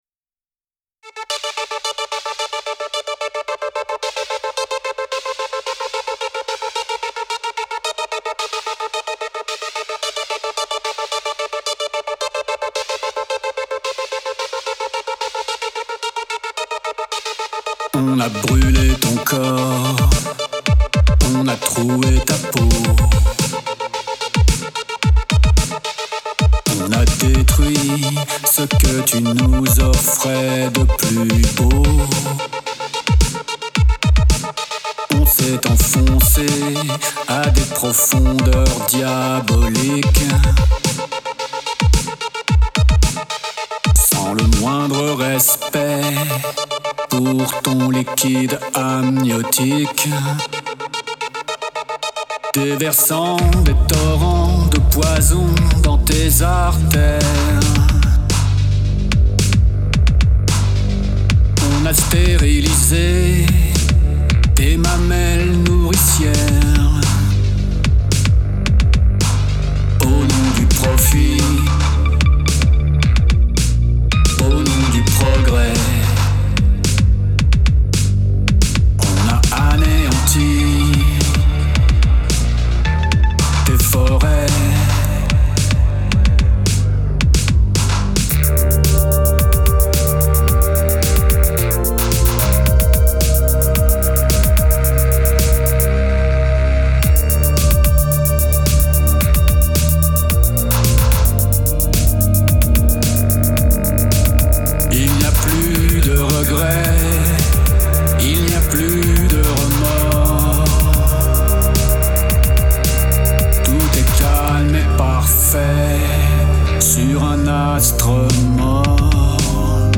Un projet avec un chanteur/compositeur datant 2015/2020 qui n'a jamais vu le jour. Je suis à la guitare/bass, co-compo, arrangement et mix.